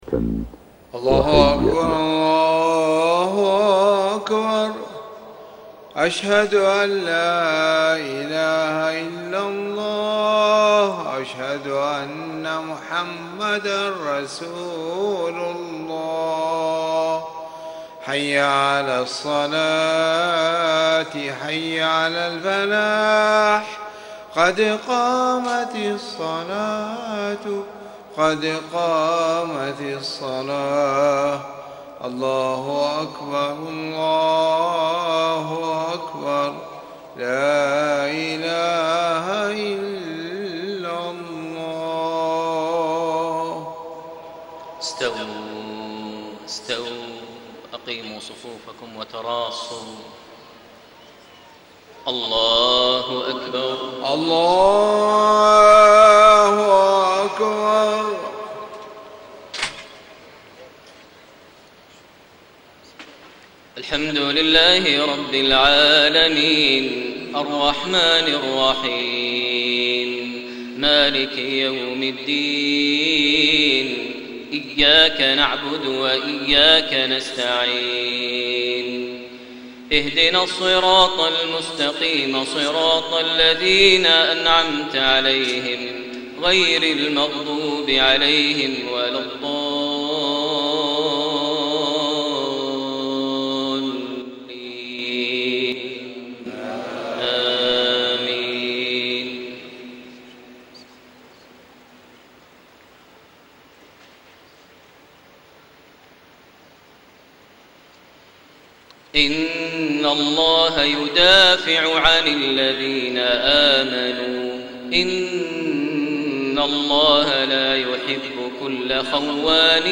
صلاة العشاء 6-8-1434 من سورة الحج 38-51 > 1434 🕋 > الفروض - تلاوات الحرمين